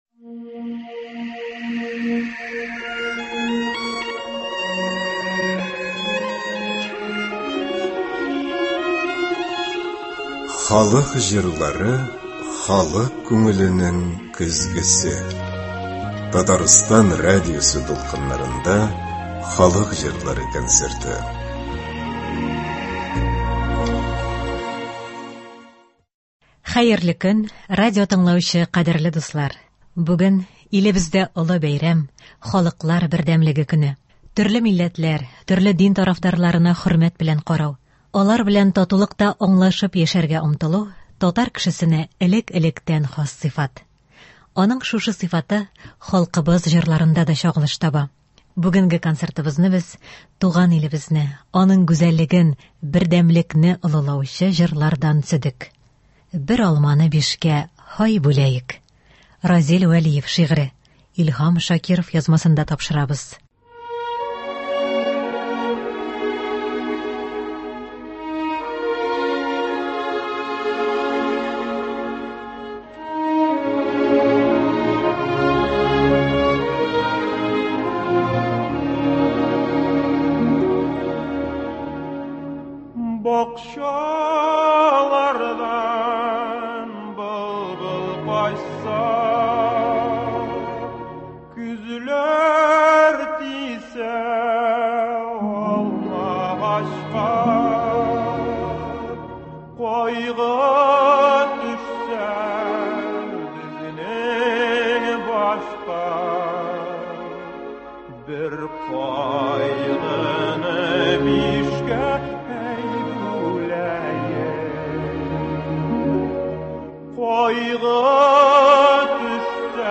Аның шушы сыйфаты халкыбыз җырларында да чагылыш таба. Бүгенге концертыбызны без туган илебезне, аның гүзәллеген, бердәмлкне олылаучы җарлырдан төзедек.